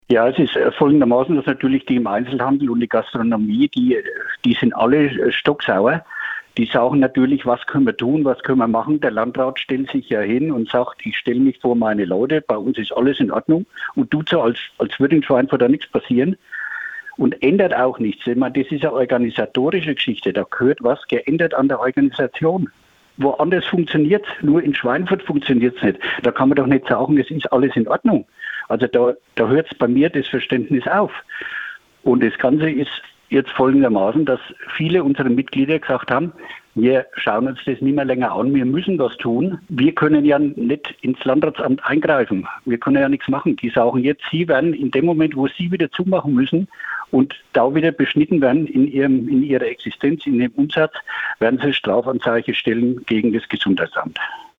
PRIMATON-Interview